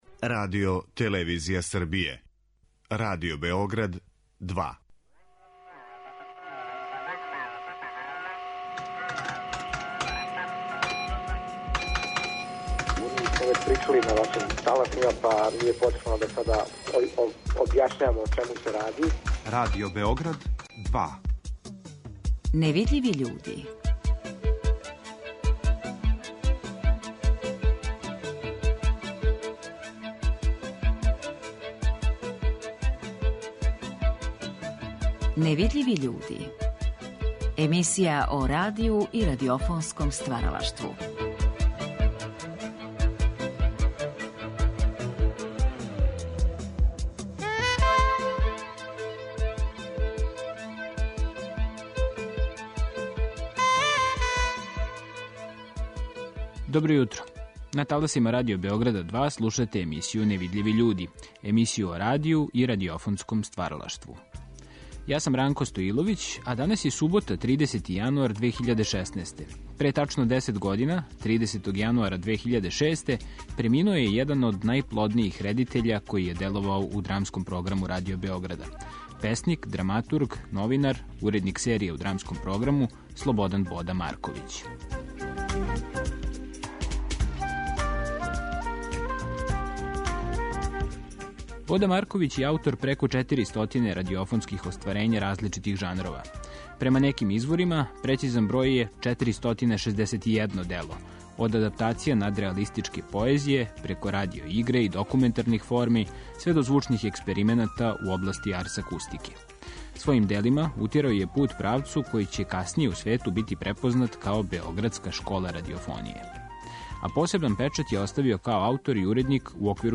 Ови разговори вођени су за циклус емисија "Гост Другог програма" 1977. године.